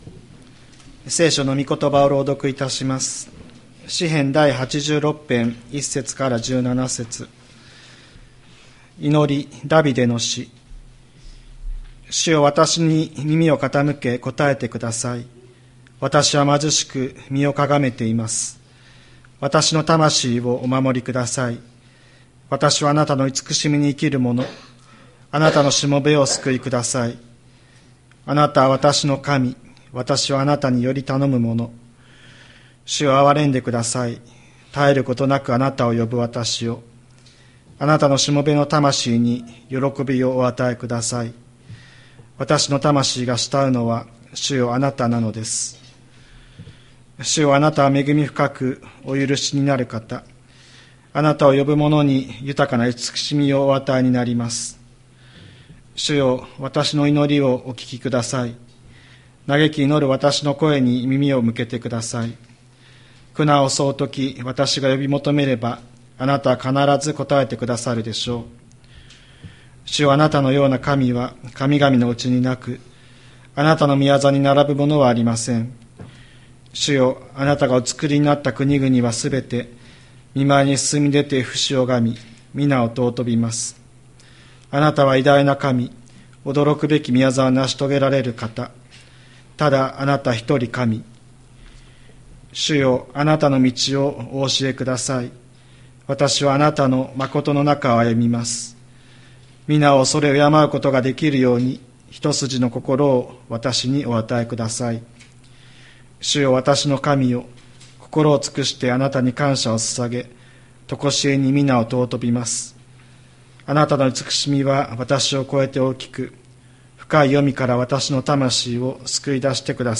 千里山教会 2025年01月05日の礼拝メッセージ。